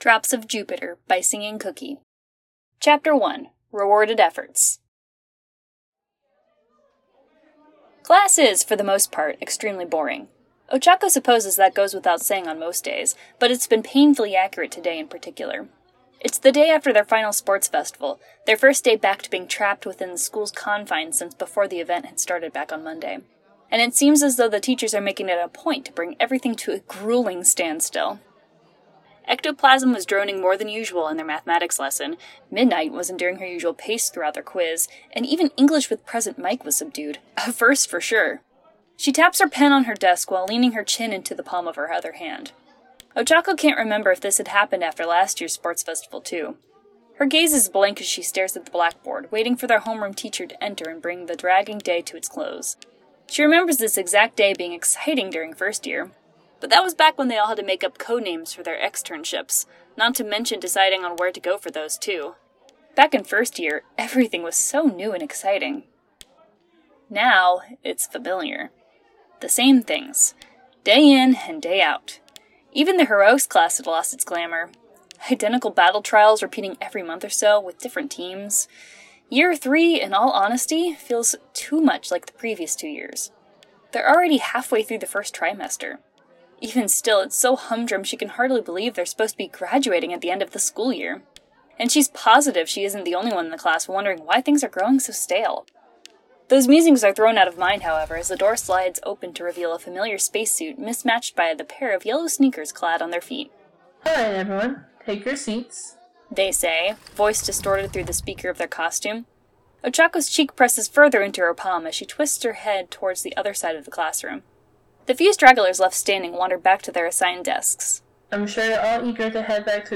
Drops of Jupiter: Chapter 1 - Rewarded Efforts | Podfic